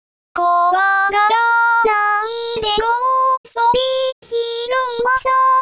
その「あ」～「ん」までを単体でなく、前の発声音の母音付きを使うのが「連続音」というものです
これにより、機械的なぶつ切れ音を低減してなめらかな発声になるというものです